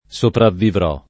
sopravvivere [ S opravv & vere ] v.